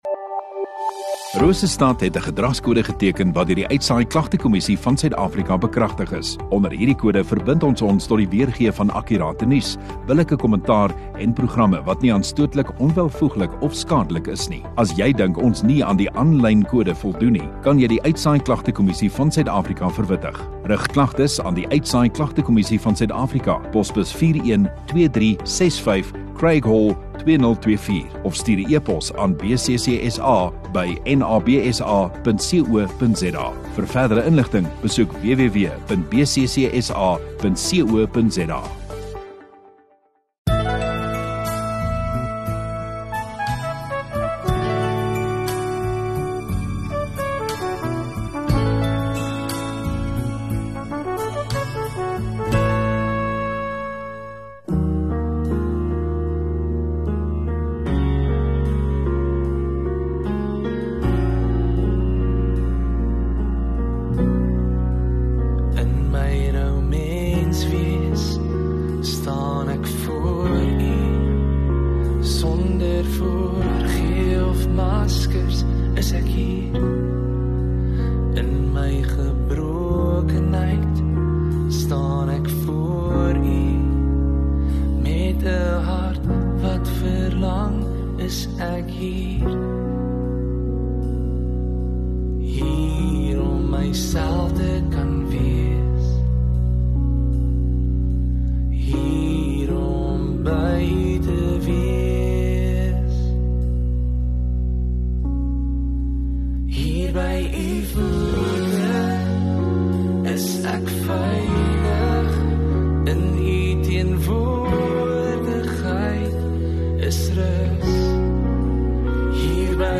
31 Aug Sondagaand Erediens